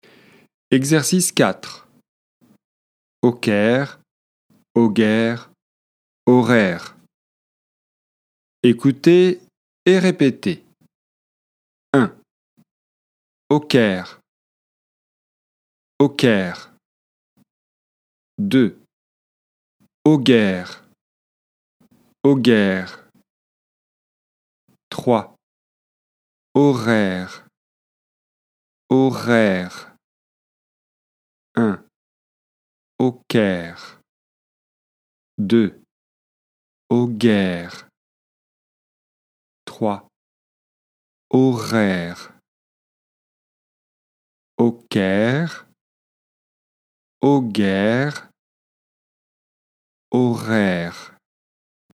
Consonne R - exercices de prononciation - FLE
Pour cela, nous partirons du son [k] puis relâcherons progressivement la tension en prononçant le son [g] et enfin le son [r] .
🎧 Exercice 4 :[okɛr] -[ogɛr]- [orɛr]
📌 Écoutez et répétez :